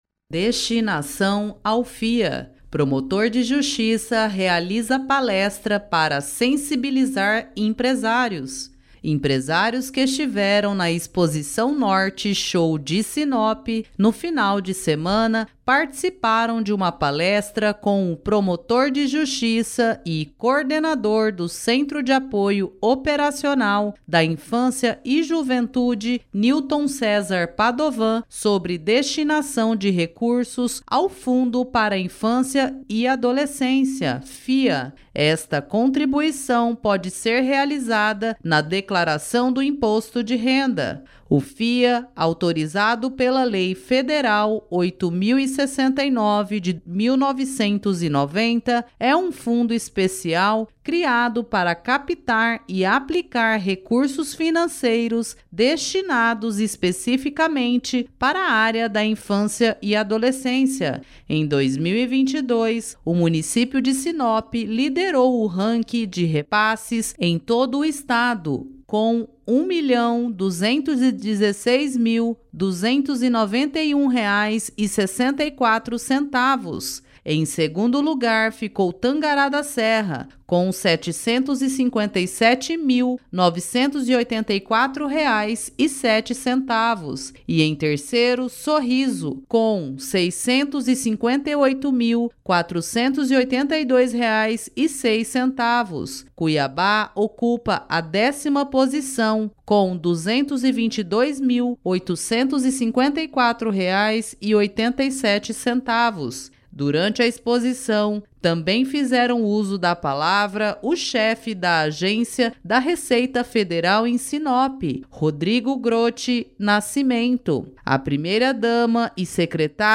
Promotor de Justiça realiza palestra para sensibilizar empresários
Empresários que estiveram na exposição Norte Show de Sinop, no final de semana, participaram de uma palestra com o promotor de Justiça e coordenador do Centro de Apoio Operacional da Infância e Juventude, Nilton Cesar Padovan, sobre destinação de recursos ao Fundo para a Infância e Adolescência (FIA).